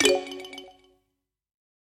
На этой странице представлены звуки восклицательного знака в разных стилях и вариациях: от стандартных системных уведомлений до необычных интерпретаций.
Звук внимания притягательный